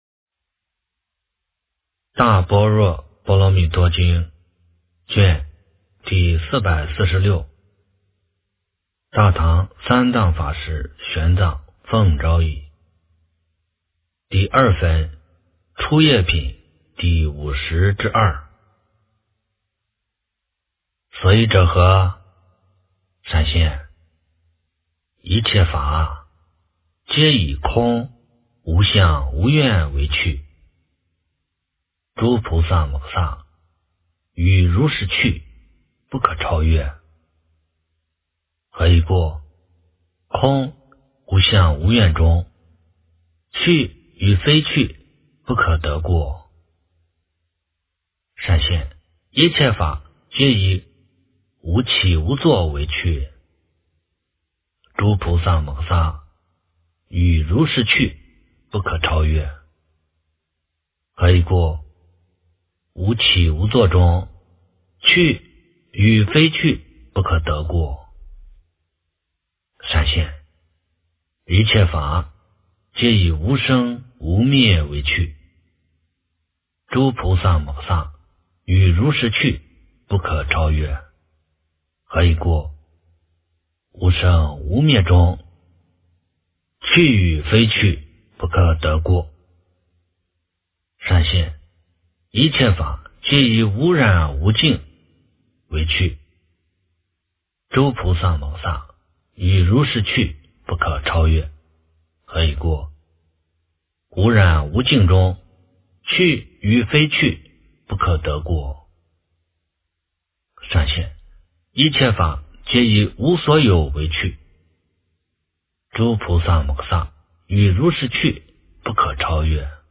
大般若波罗蜜多经第446卷 - 诵经 - 云佛论坛